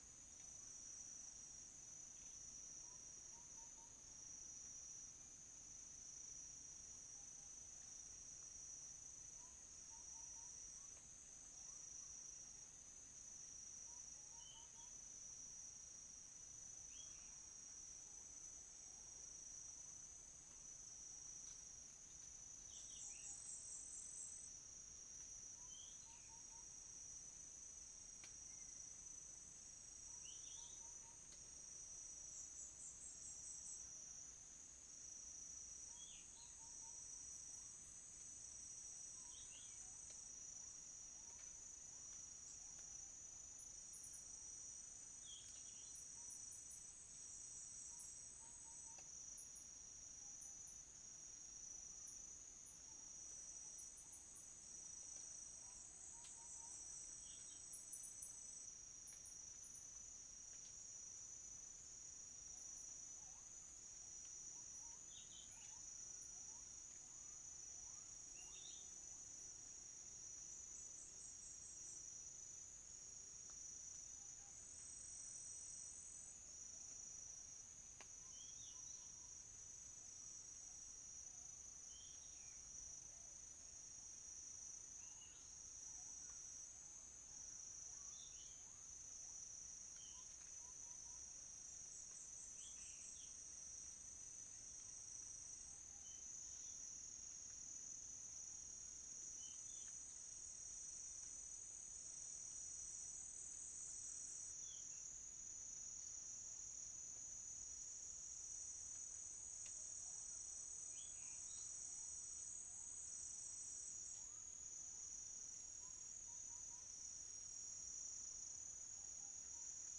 Centropus sinensis
Pycnonotus goiavier
Copsychus saularis